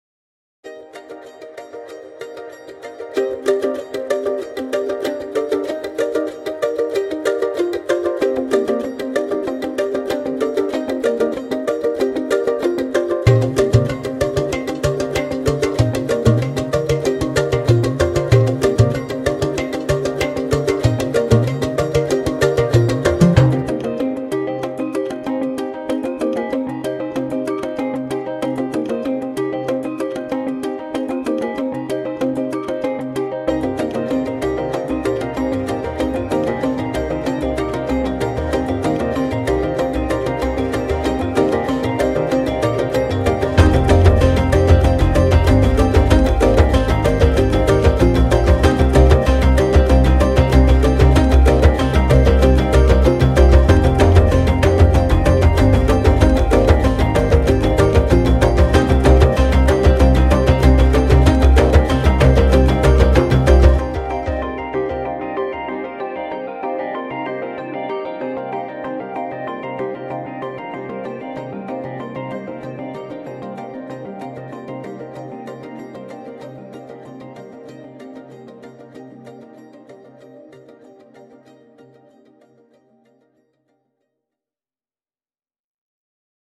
该库包括 4 类声音：弹拨弦、合成器实验、鼓舞人心的动作和打击乐循环。
打击乐循环类别包括用udu，皮肤鼓，tambuata和地板汤姆制成的循环。
主要灵感来自部落舞曲